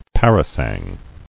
[par·a·sang]